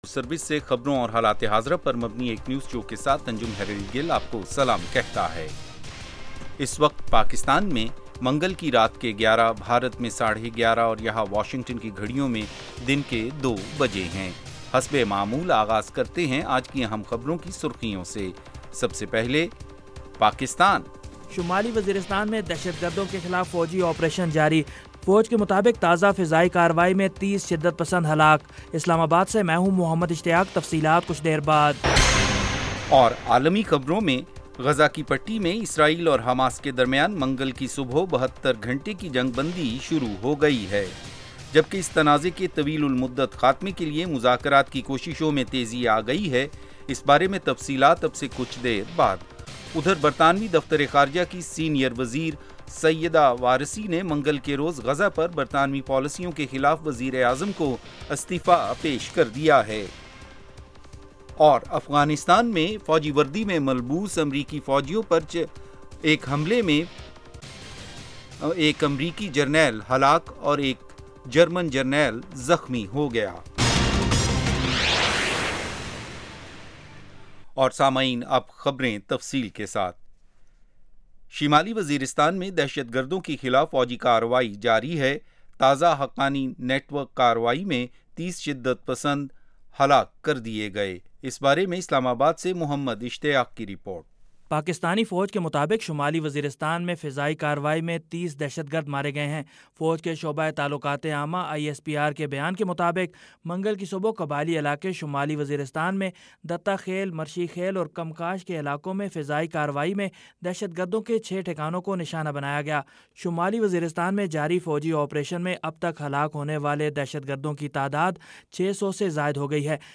اس ایک گھنٹے کے پروگرام میں دن بھر کی اہم خبریں اور پاکستان اور بھارت سے ہمارے نمائندوں کی رپورٹیں پیش کی جاتی ہیں۔ اس کے علاوہ انٹرویو، صحت، ادب و فن، کھیل، سائنس اور ٹیکنالوجی اور دوسرے موضوعات کا احاطہ کیا جاتا ہے۔